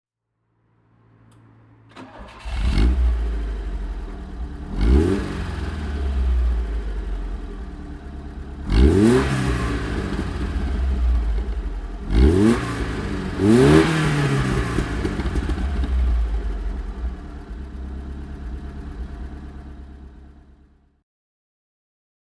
Alfa Romeo Alfasud Sprint (1977) - Starten und Leerlauf